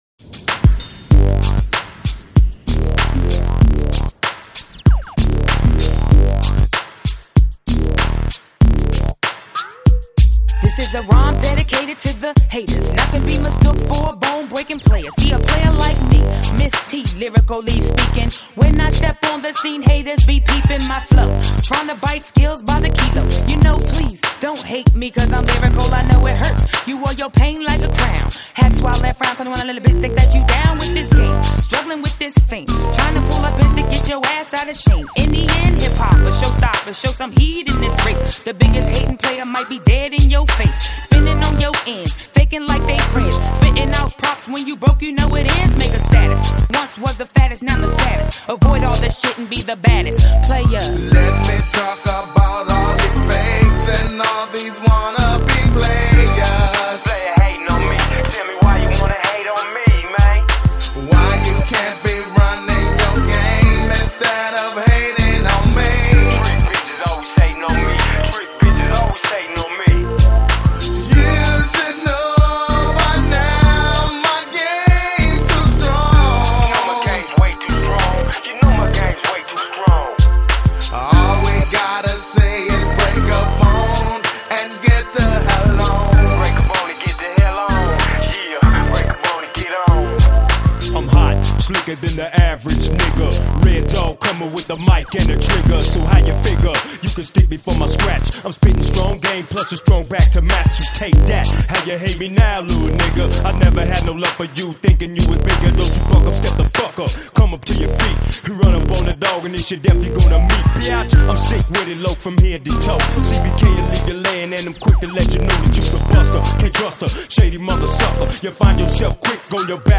Жанр: Rap, Hip Hop